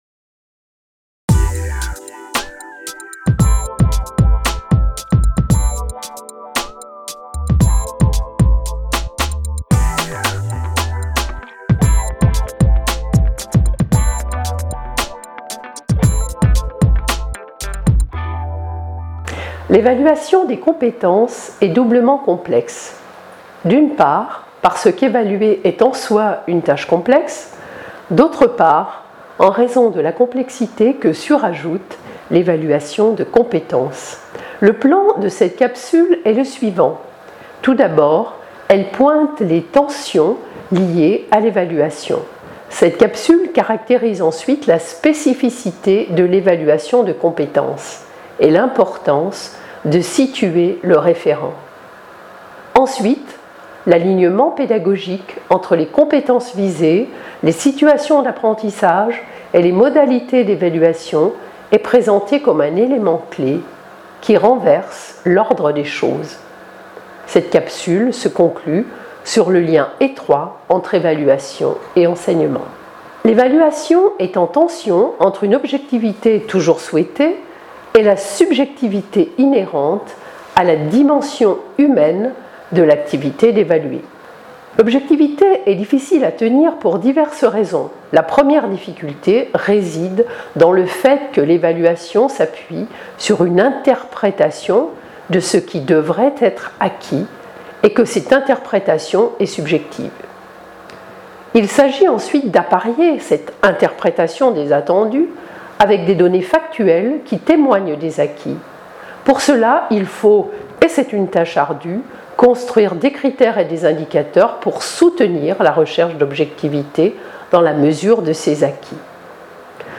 Vidéo pédagogique